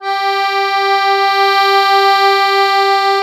MUSETTE1.7SW.wav